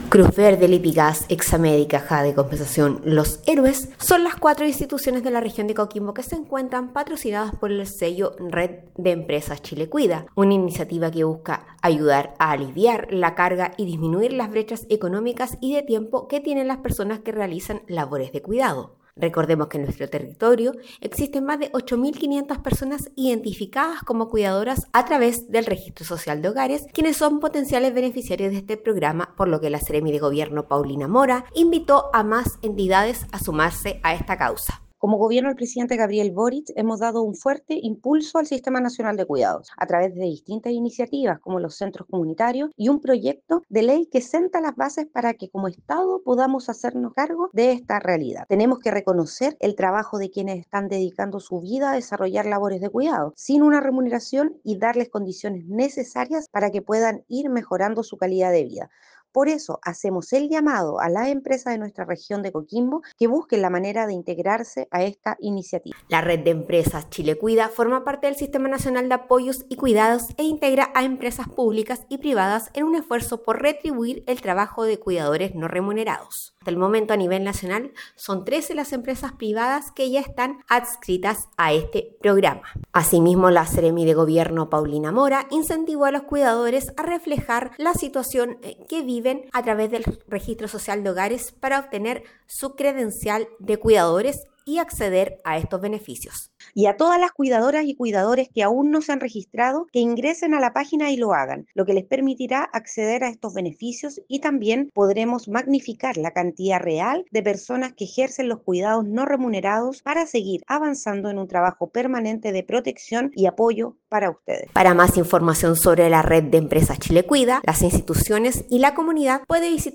DESPACHO-RED-CHILE-CUIDA.mp3